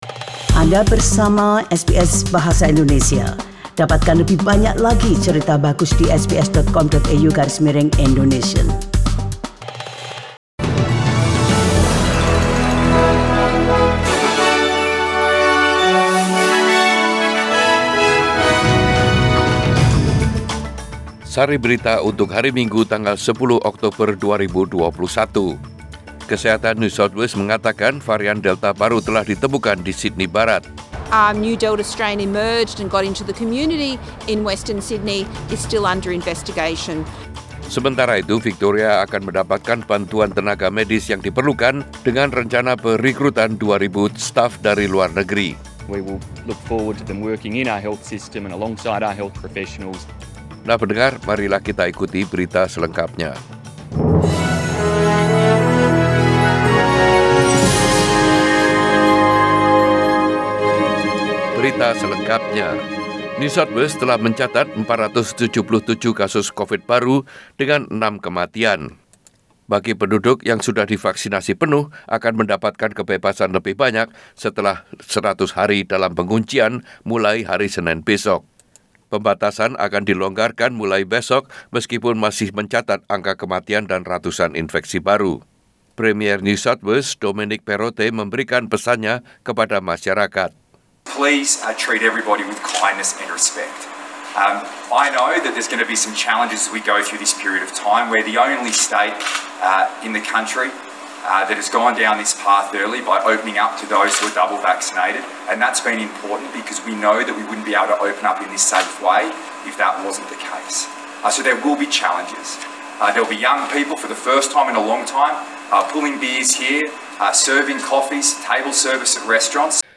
Warta Berita Radio SBS Program Bahasa Indonesia Source: SBS